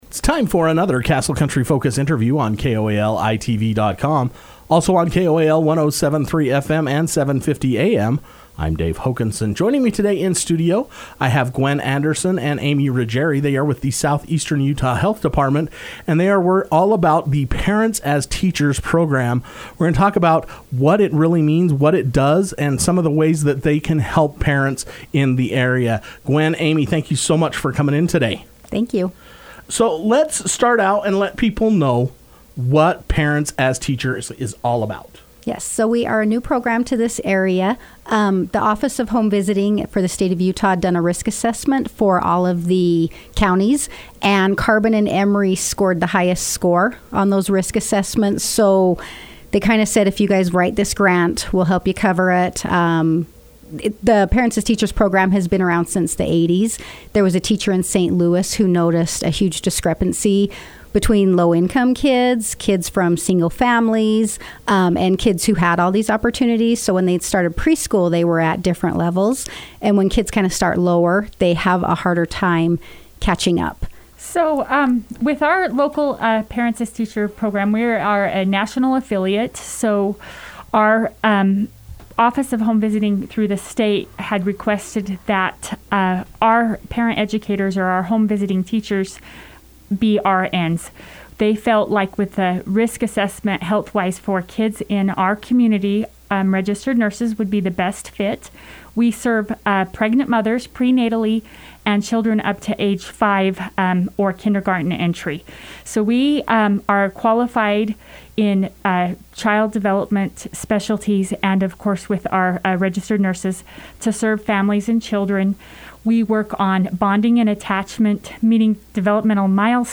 both took the time to come into Castle Country Radio to share details about the new program.